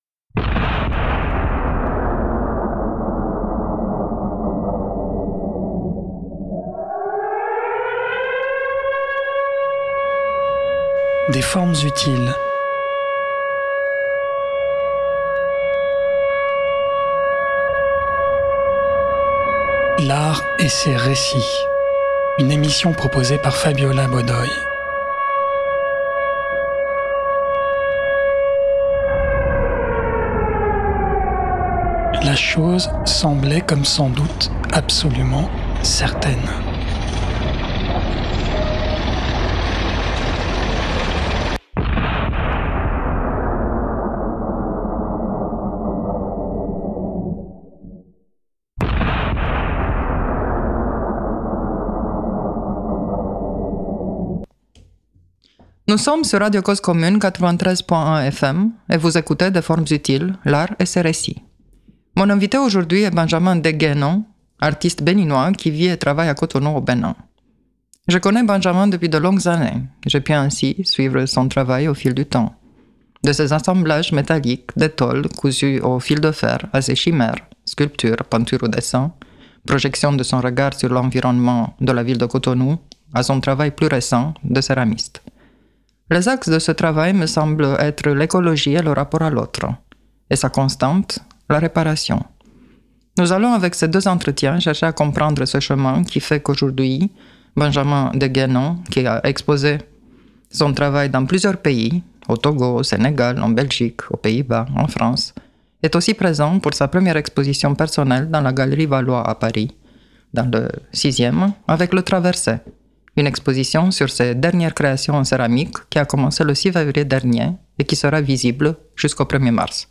S’abonner au podcast 232e émission Libre à vous ! de l’ April diffusée en direct mardi 21 janvier 2025 de 15 h 30 à 17 h 00 Au programme de l’émission: sujet principal : le collectif Alpes Numérique Libre. Un collectif de directeur de systèmes d’information de collectivités autour de Grenoble